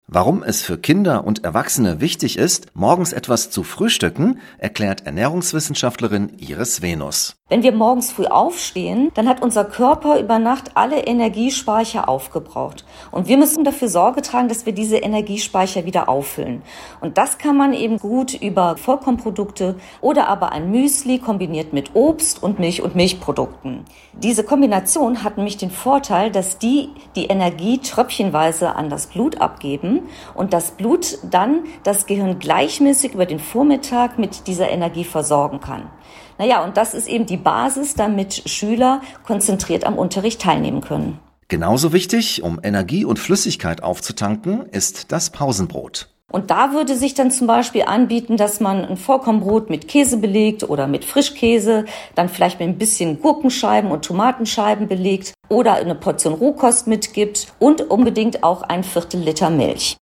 rts-beitrag-pausenbrot.mp3